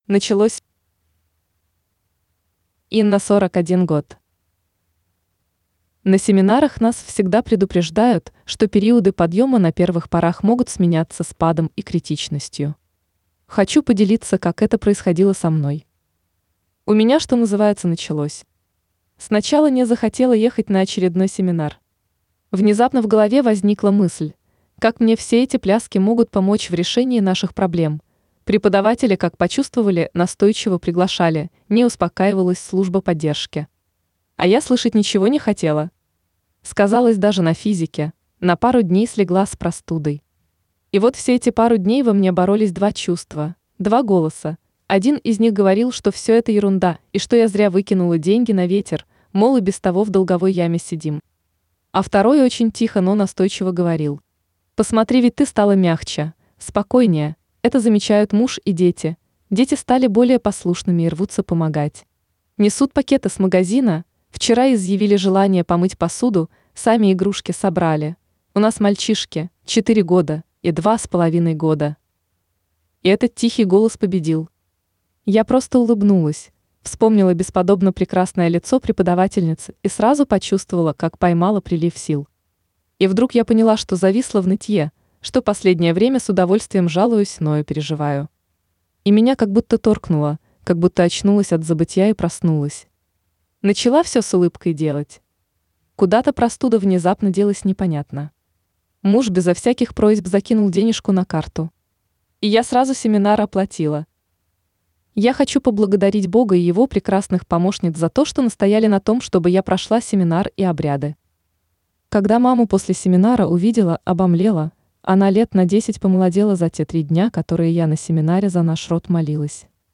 Жанр: Аудио книга.